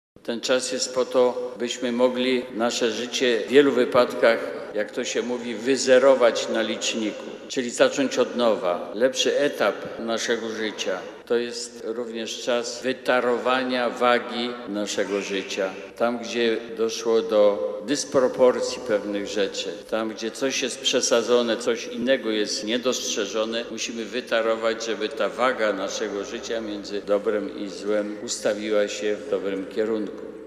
Pątniczy szlak zainaugurowała uroczysta liturgia, w czasie której bp warszawsko-praski życzył by czas wędrówki stał się czasem przemiany serca i życia.